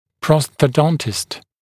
[ˌprɔsθə’dɔntɪst][ˌпроссэ’донтист]ортопед